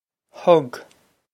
Thug Hug
This is an approximate phonetic pronunciation of the phrase.